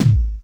Kick_61.wav